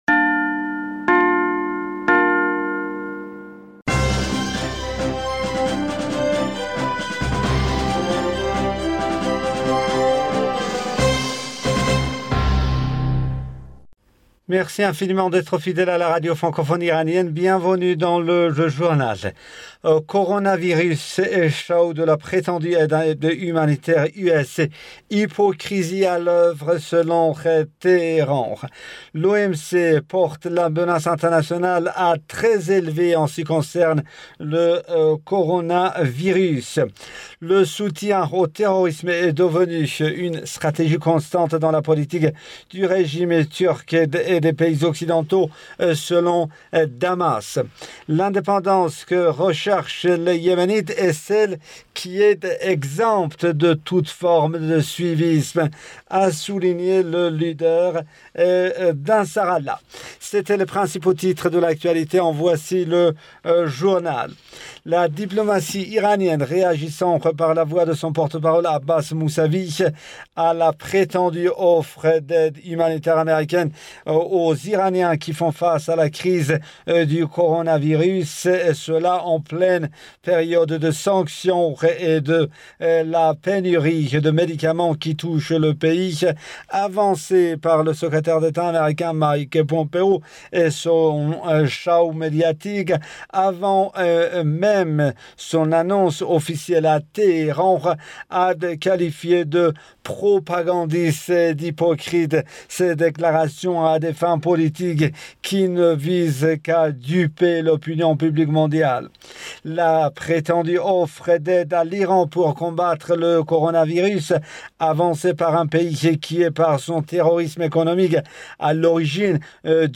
Bulletin d'information du 29 février 2020